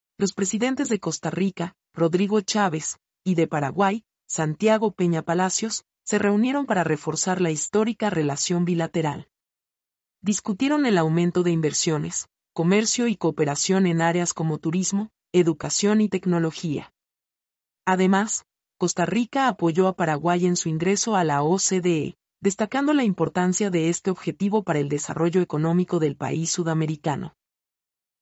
mp3-output-ttsfreedotcom-86-1.mp3